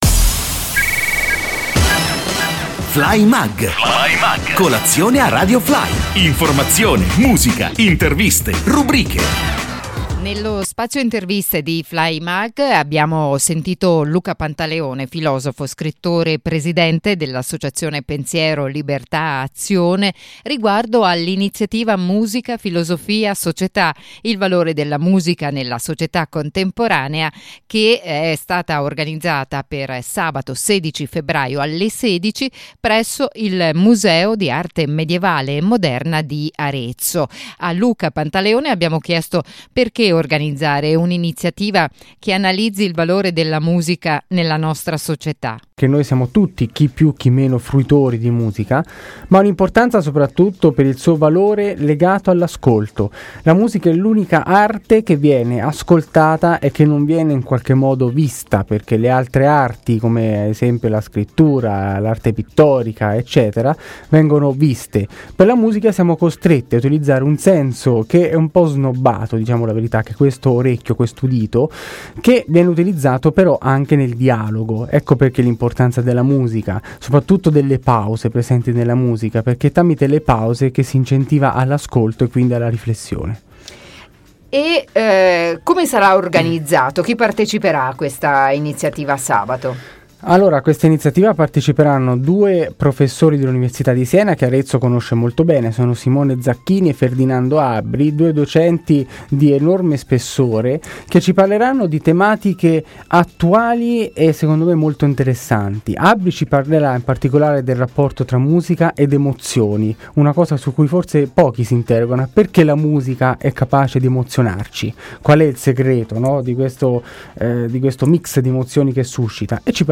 FLYMUG, INTERVISTA